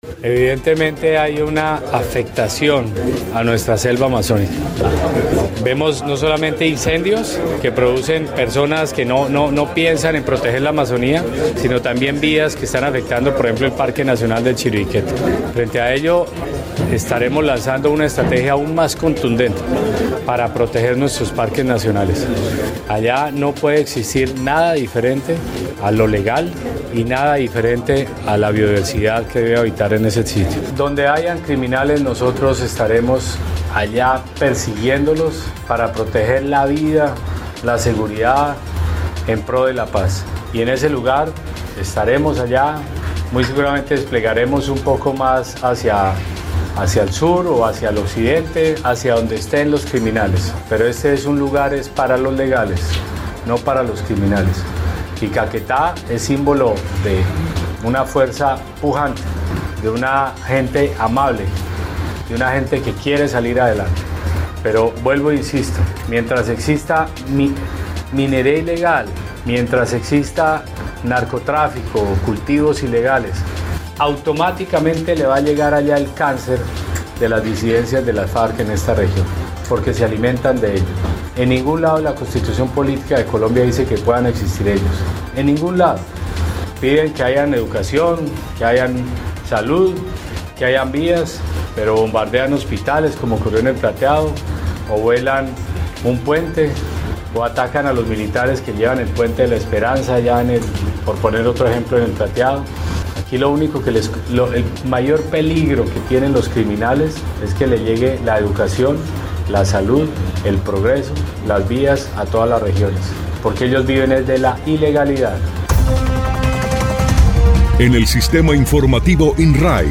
01_MINISTRO_PEDRO_SANCHEZ_TEMAS.mp3